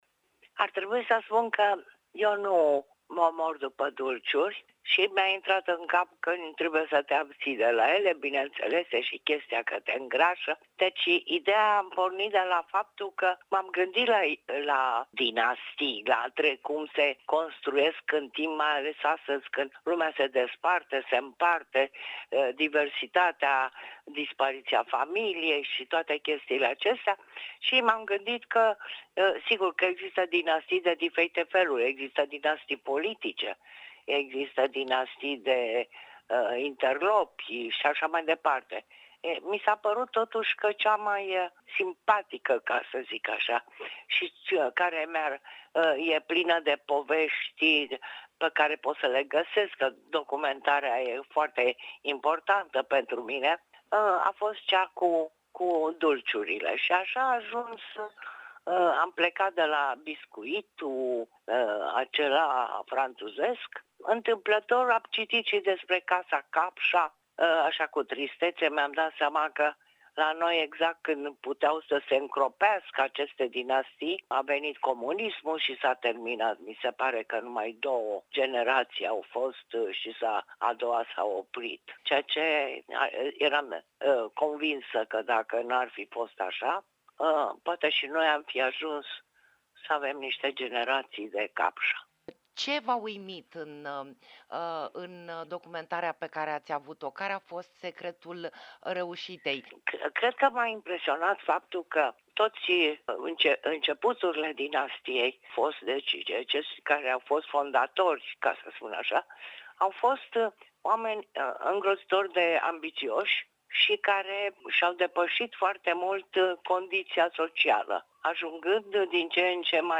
Interviu-scurt-Liiceanu.mp3